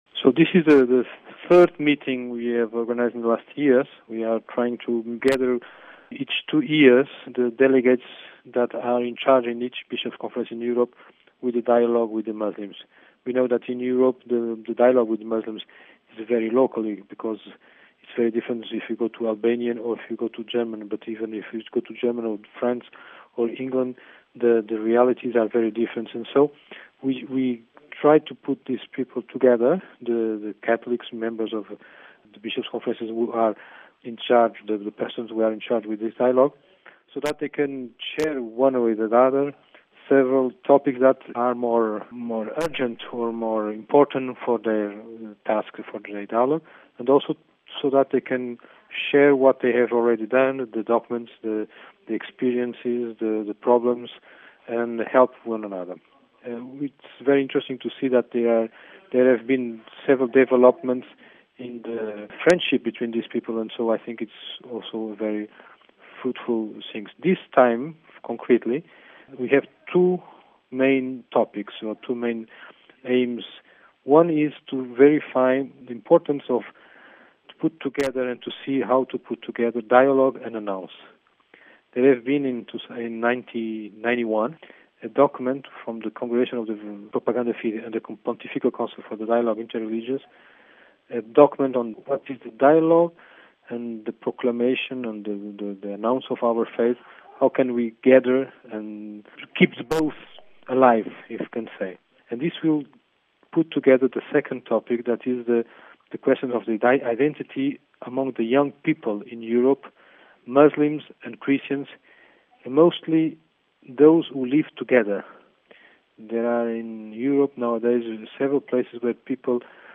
(Vatican Radio) Is dialogue with Muslims possible as an integral part of the Church’s new evangelization?